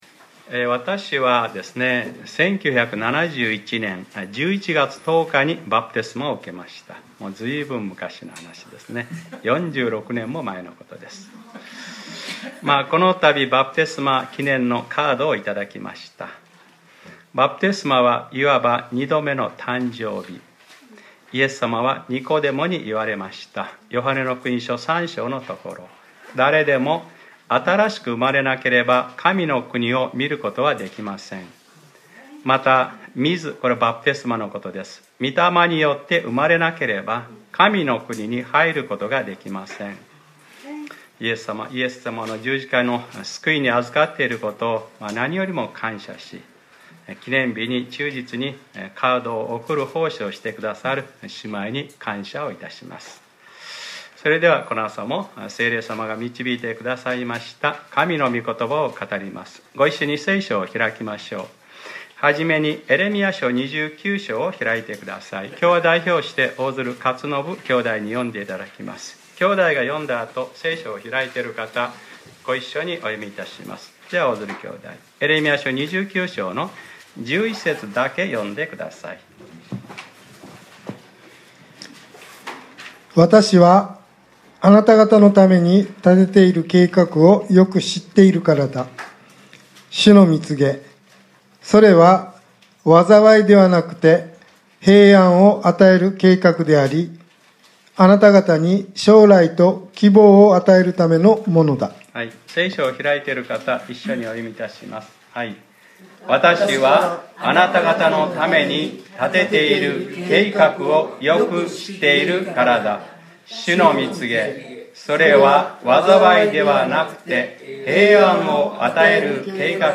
2017年11月12日（日）礼拝説教『将来と希望を与えるためのものだ』